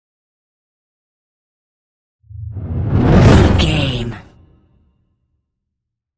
Whoosh deep
Sound Effects
dark
futuristic
whoosh